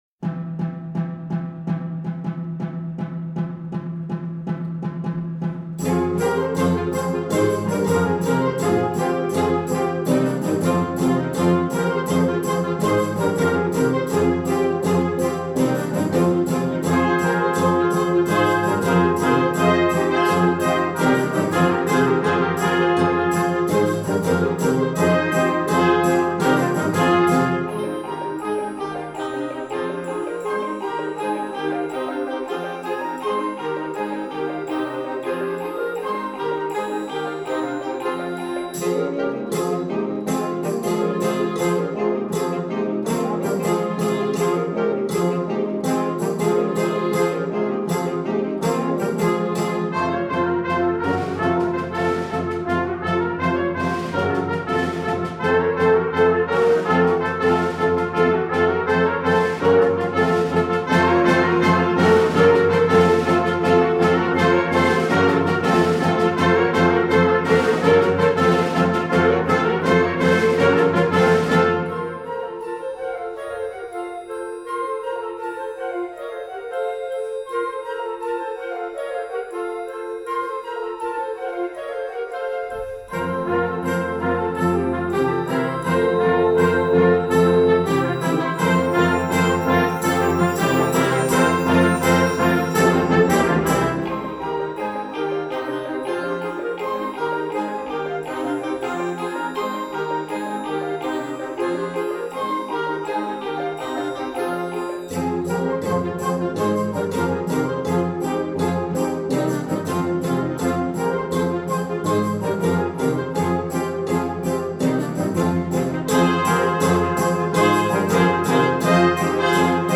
Composer: Bulgarian Folk Dance
Voicing: Concert Band